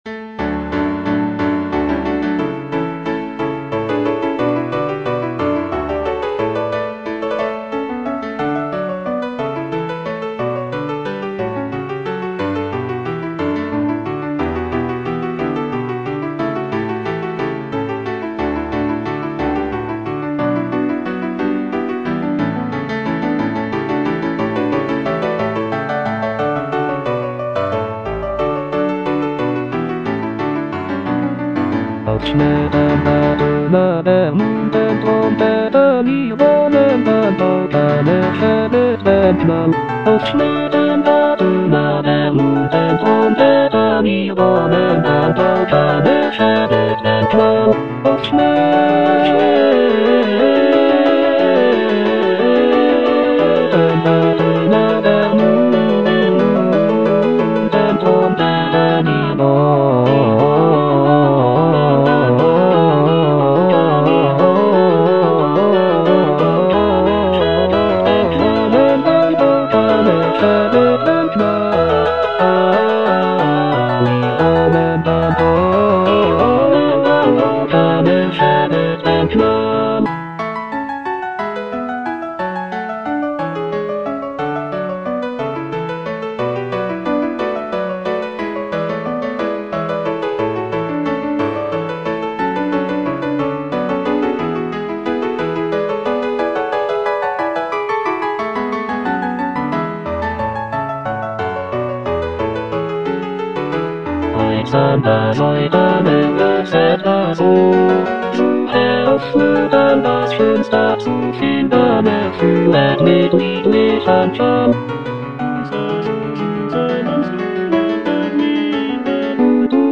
Cantata
Bass (Emphasised voice and other voices) Ads stop